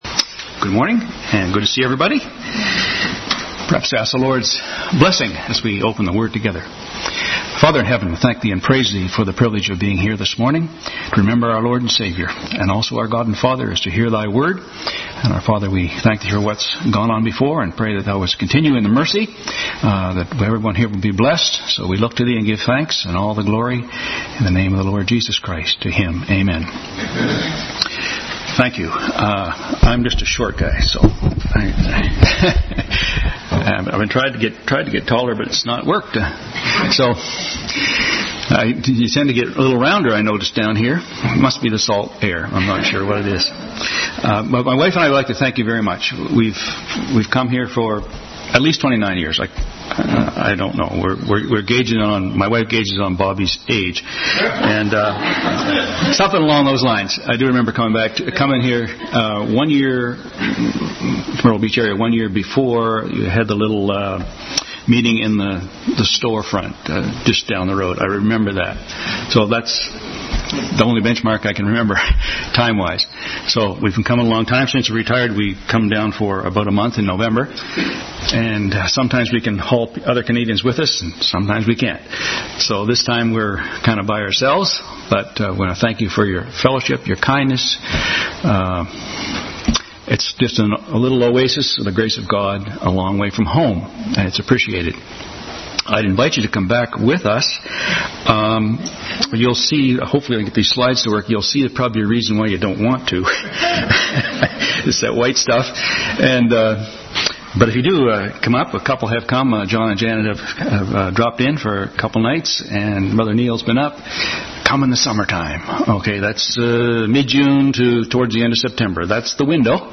Bible Text: Matthew 28:18-20, Mark 16:14-20, Luke 24:44-53, John 20:20-23 | Family Bible Hour message, “The Great Commission”.